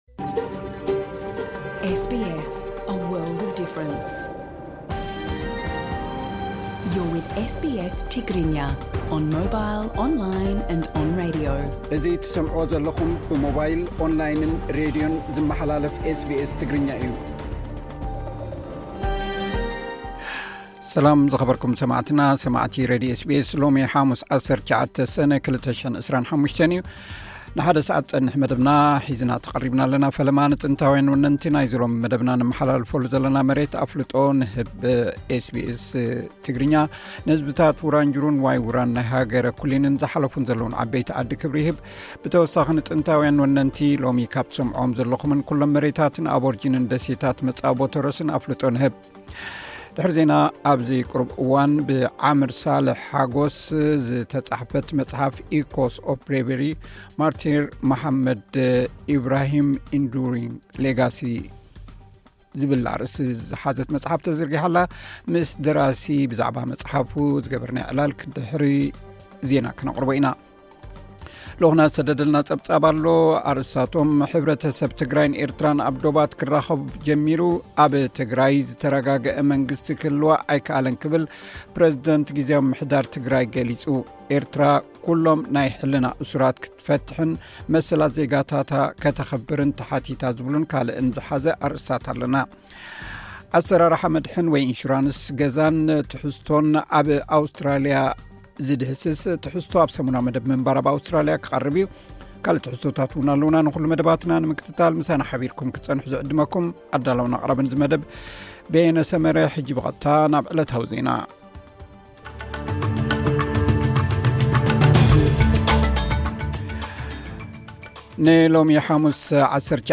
ዕለታዊ ዜና ኤስ ቢ ኤስ ትግርኛ (19 ሰነ 2025)